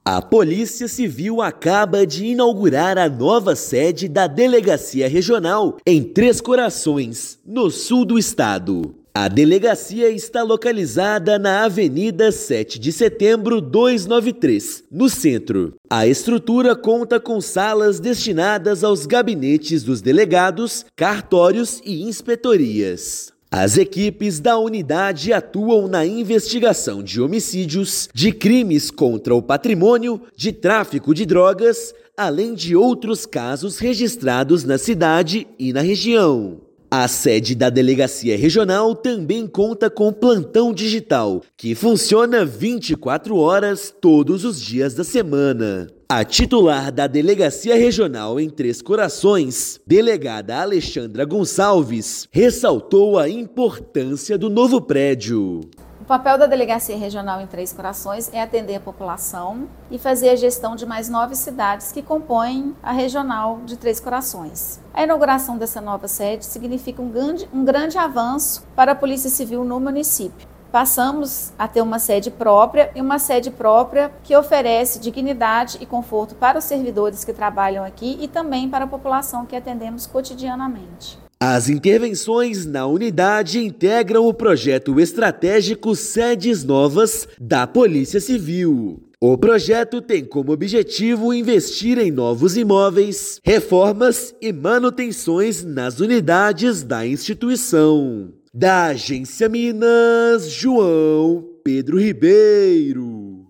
Novas instalações proporcionam melhores condições para o atendimento ao cidadão e a execução dos trabalhos de investigação e polícia judiciária. Ouça matéria de rádio.